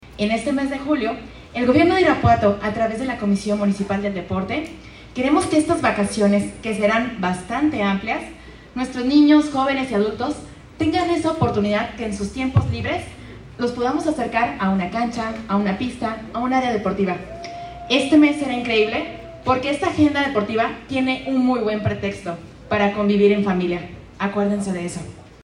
Janet Estrada Ponce, directora de la Comudaj